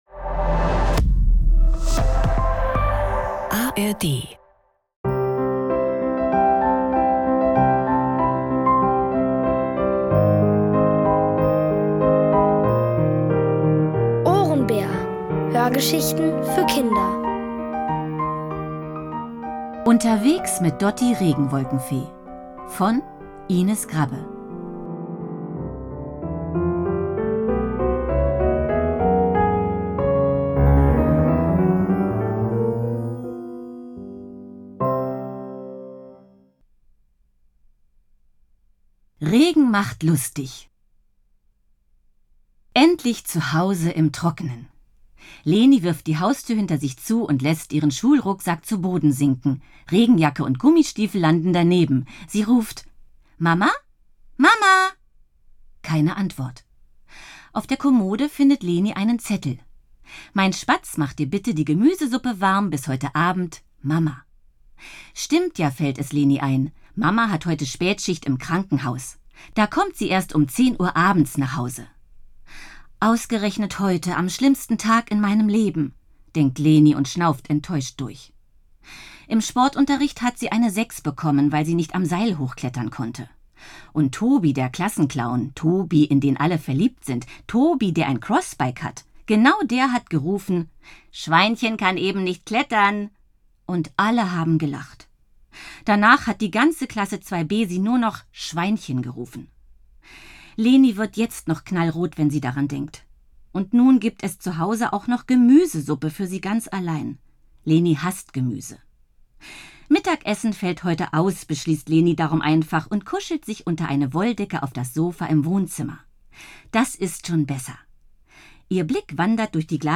Alle 5 Folgen der OHRENBÄR-Hörgeschichte: Unterwegs mit Dotti Regenwolkenfee von Ines Grabbe.